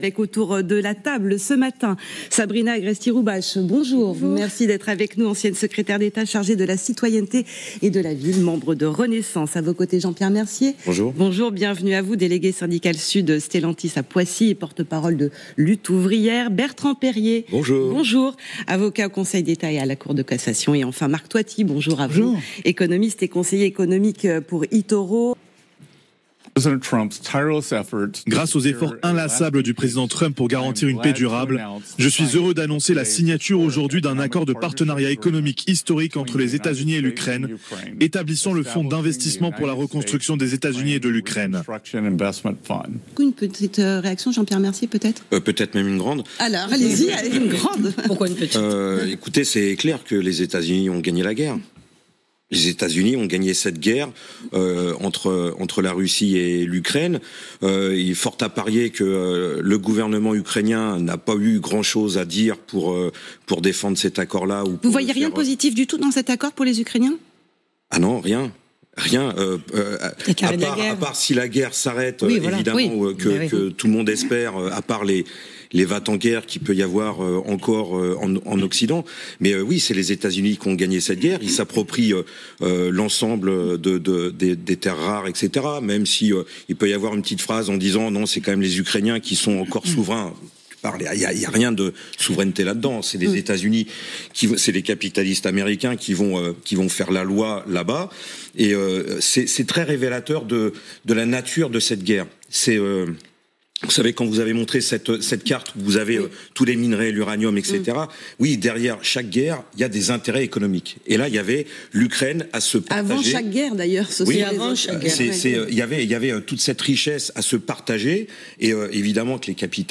Débat sur l'actualité